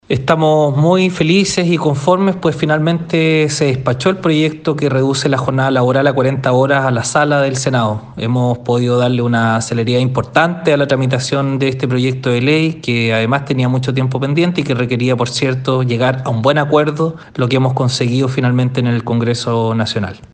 Al respecto, el Seremi del Trabajo y Previsión Social, Ángel Cabrera, señaló: